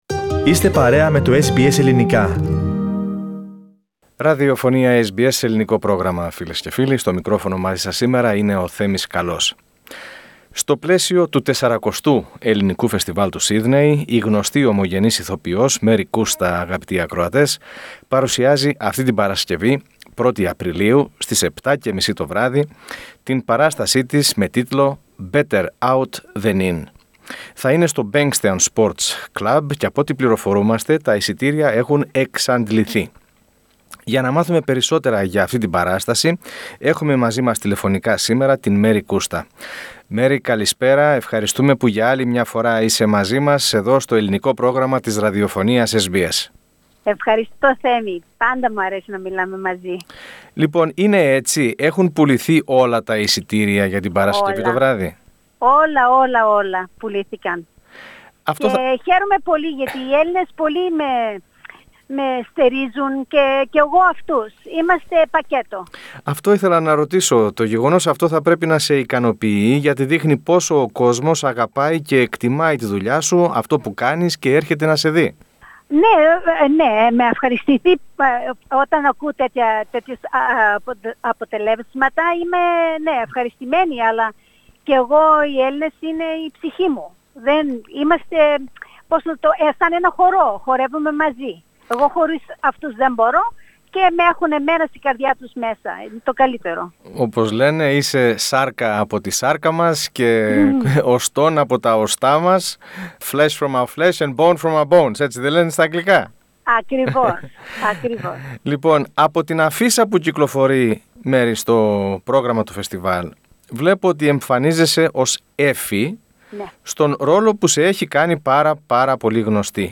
Στην συνέντευξη που παραχώρησε στο SBS Greek, η κυρία Κούστα είπε ότι αυτή η παράσταση περιέχει πολλά αστεία που τα εμπνεύστηκε την περίοδο εγκλεισμού λόγω της πανδημίας και αρκετά μιλούν ευθέως για τον κορωνοϊό.